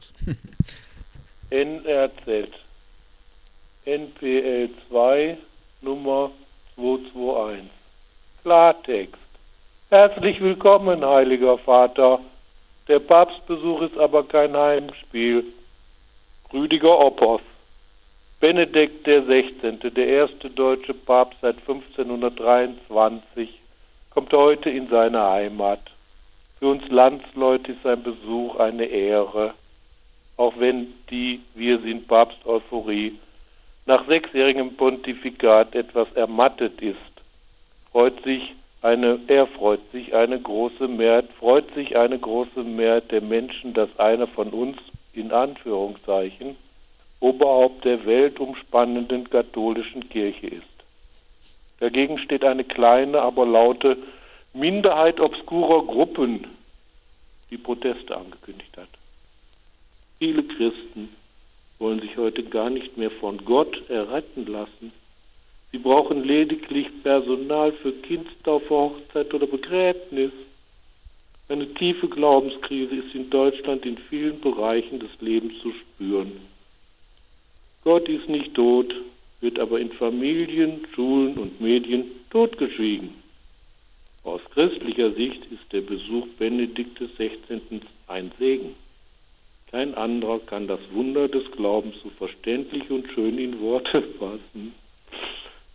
[aus einem Leitartikel der NRZ]